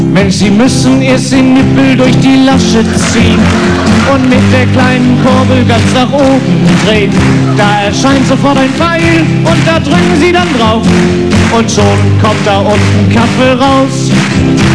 lustigen Lied